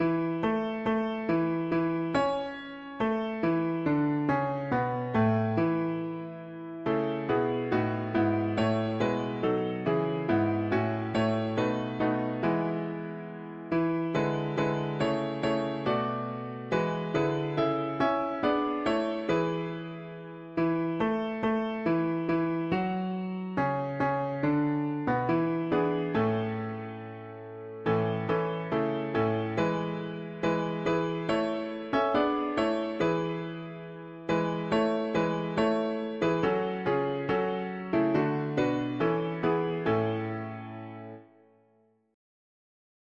Key: A major Meter: 76.76 D with refrain